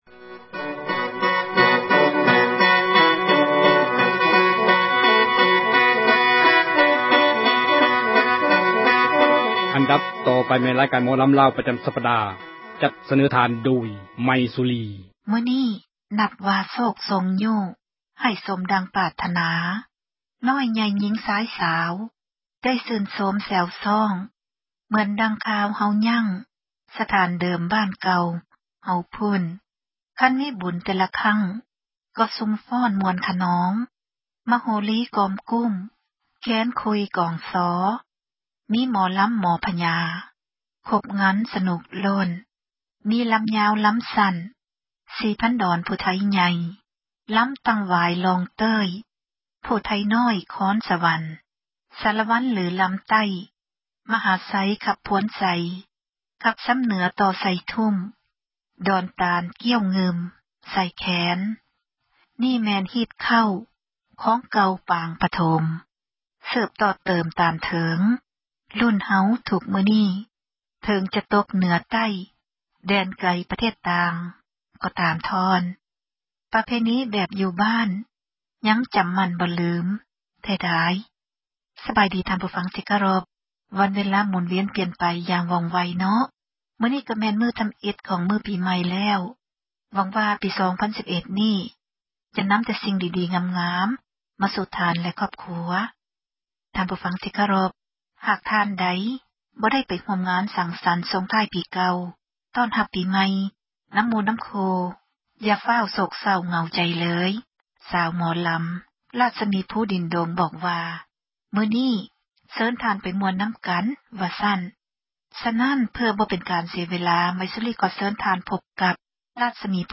ໝໍລໍາ